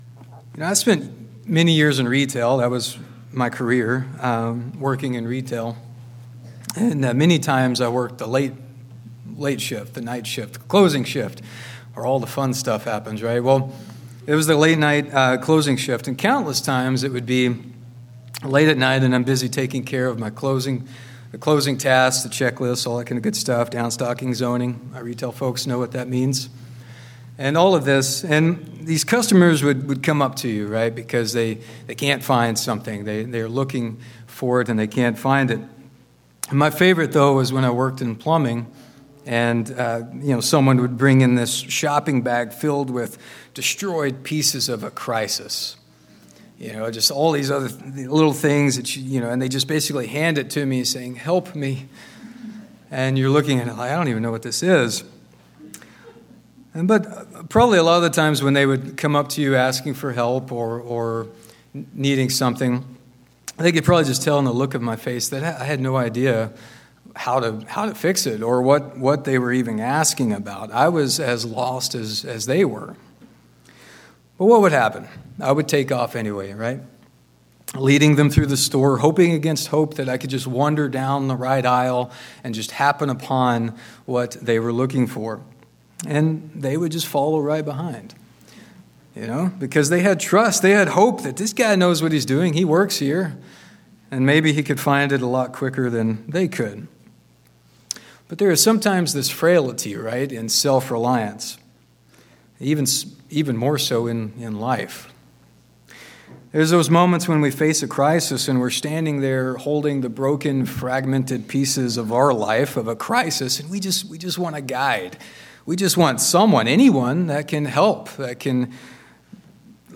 Sermons
Given in Spokane, WA Chewelah, WA Kennewick, WA